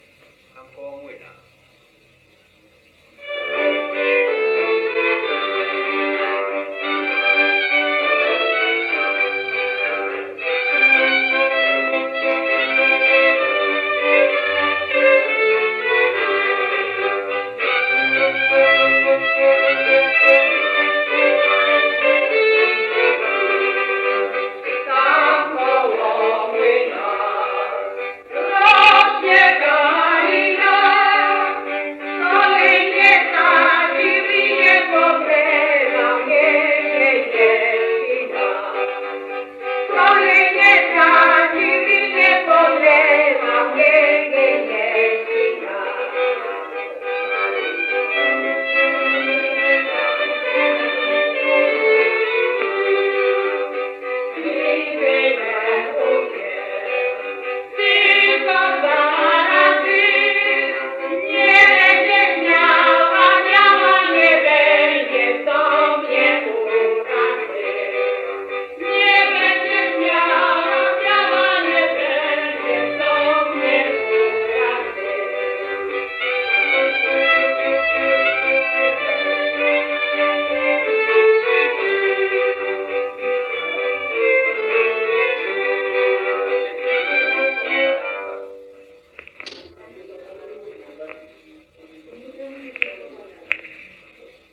Tam koło młyna – Żeńska Kapela Ludowa Zagłębianki
Nagrania archiwalne (I skład kapeli)